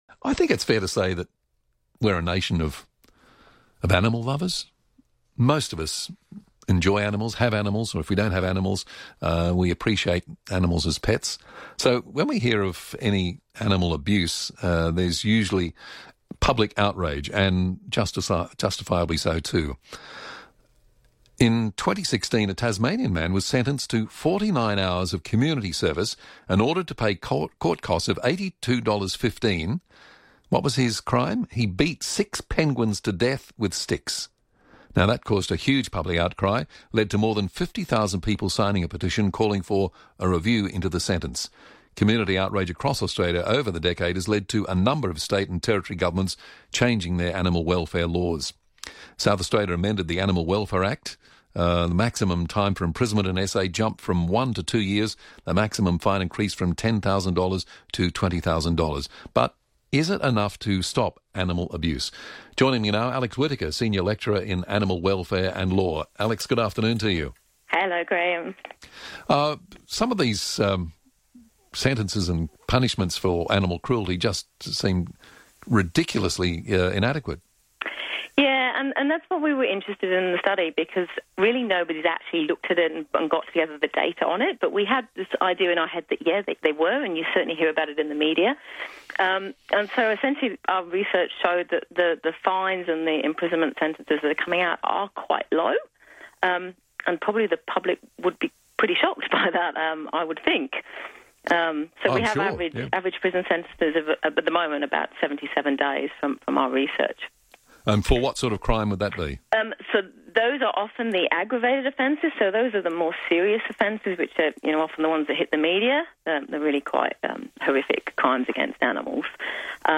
We're on Talkback Radio